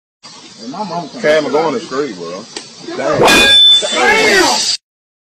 Metal Slam